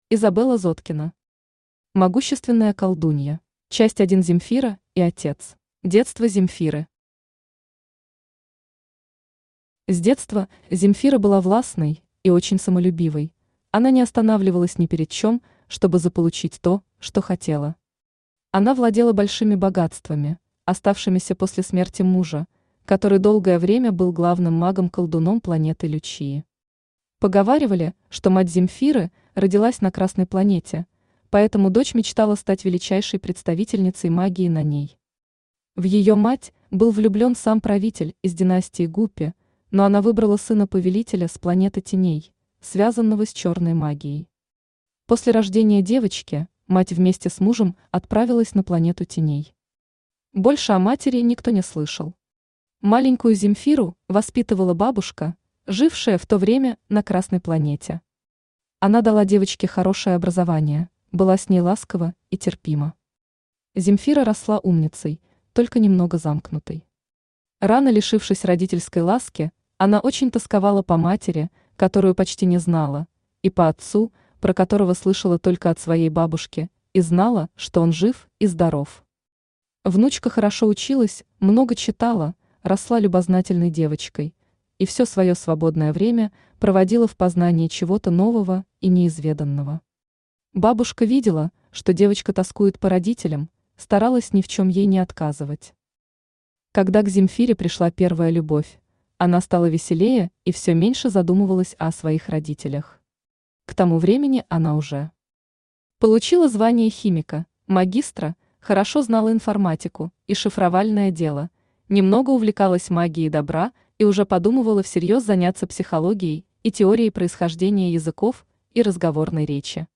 Аудиокнига Могущественная колдунья | Библиотека аудиокниг
Aудиокнига Могущественная колдунья Автор Изабелла Зоткина Читает аудиокнигу Авточтец ЛитРес.